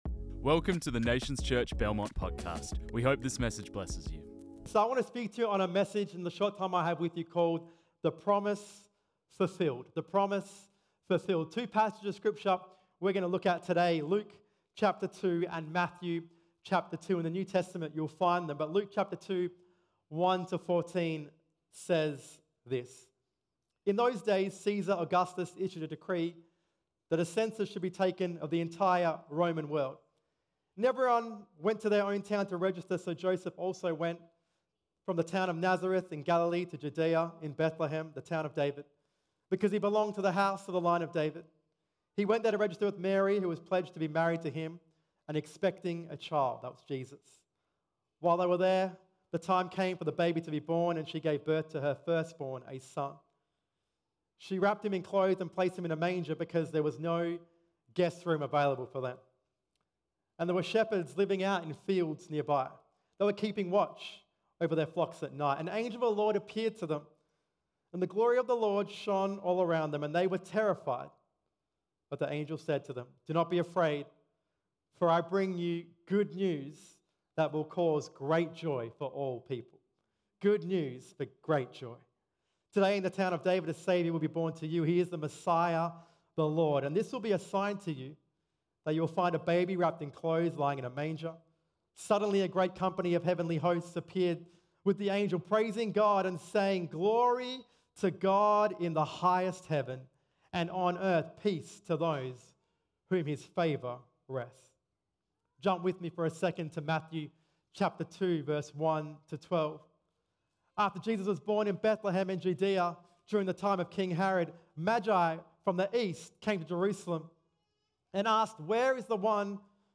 This message was preached on 24 December 2024.